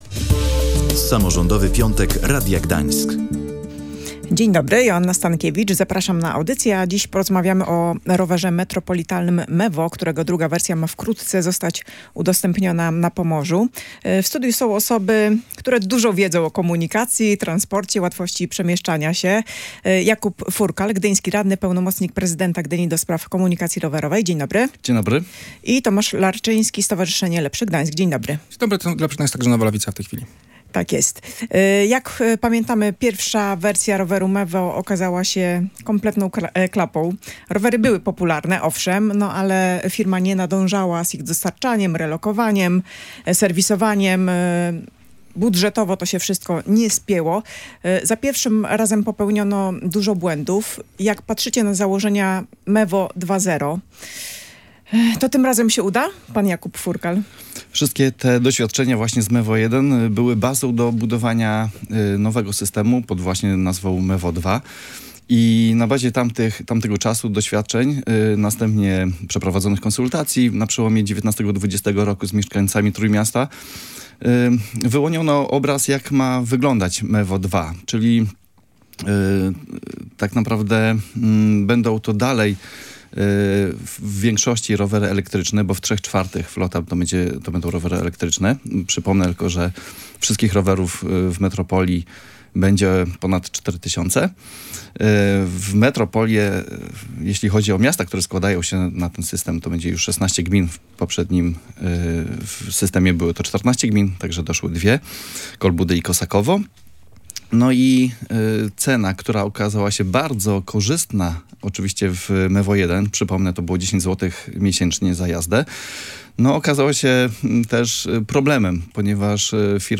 Mevo 2.0 – przemyślane uzupełnienie transportu publicznego, czy kolejny kryzys? Dyskusja ekspertów